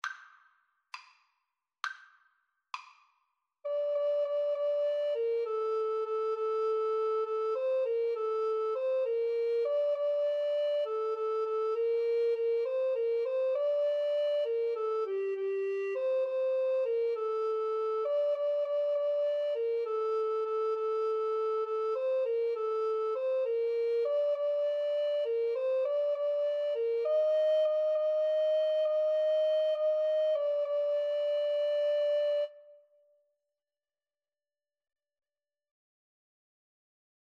6/8 (View more 6/8 Music)
Classical (View more Classical Alto Recorder Duet Music)